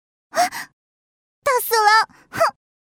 GameAngryAudio.mp3